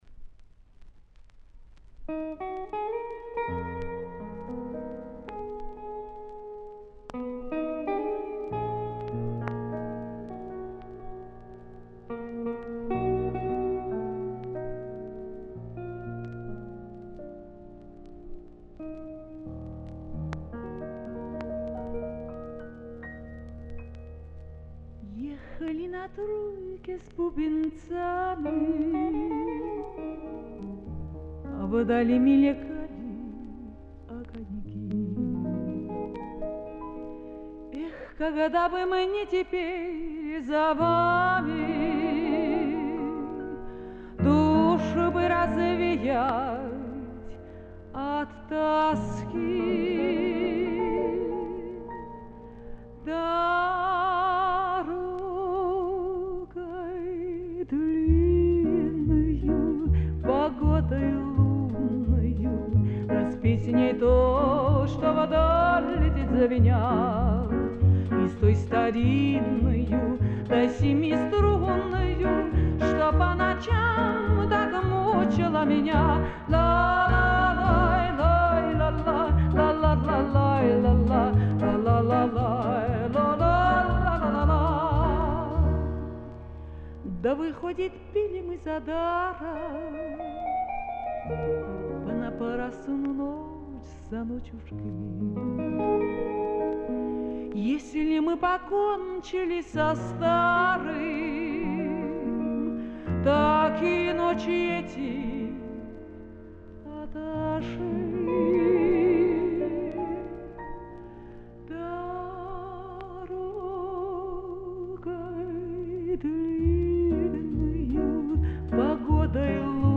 Винил